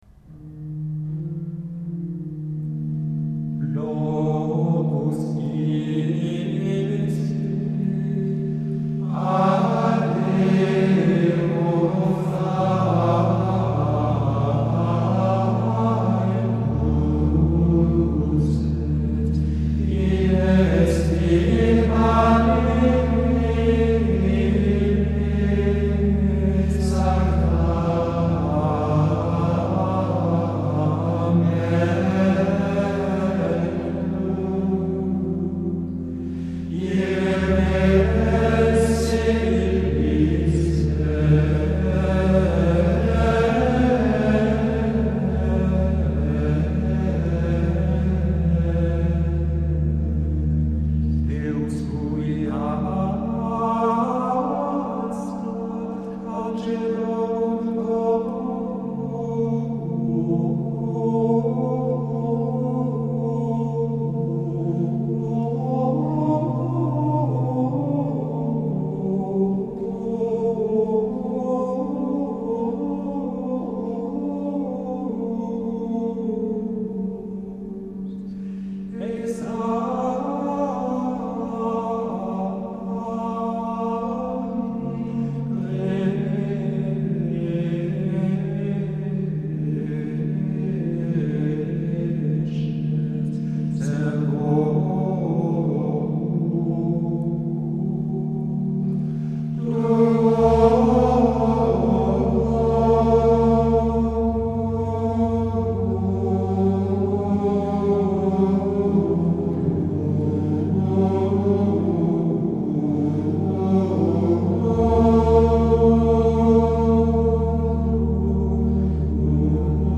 L’intonation est douce et grave, comme dans la plupart des graduels du 5ème mode.
Le mouvement est léger toutefois, sans lenteur, mais très legato et paisible.
C’est de la grande louange qu’il faudra bien épanouir en son sommet, mais continuer ensuite dans un tempo léger jusqu’au bout et presque sans ralentir à la fin.
La finale du graduel utilise la formule classique du 5ème mode sur servorum tuorum qui nous fait reprendre un peu de mouvement et d’entrain joyeux pour conclure l’ensemble de la prière dans la confiance et l’allégresse.
un moine de Triors